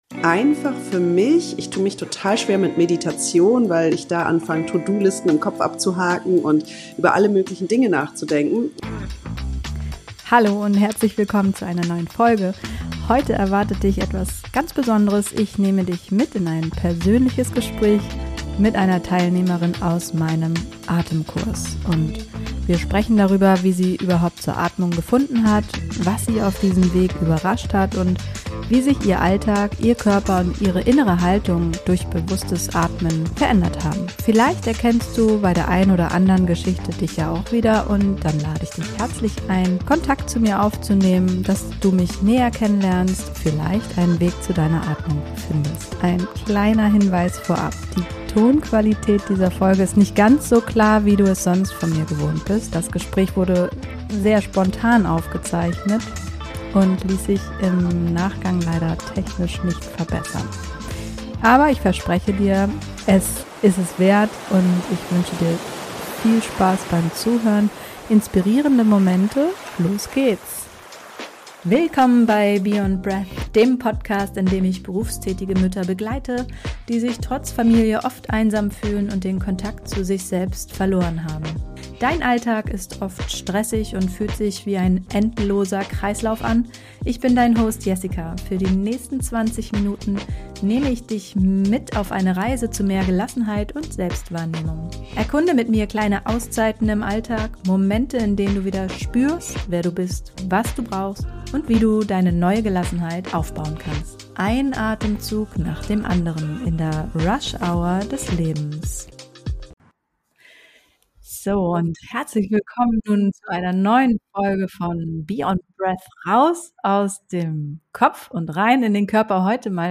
In dieser Folge nehme ich dich mit in ein Gespräch mit einer Teilnehmerin aus meinem Atemkurs.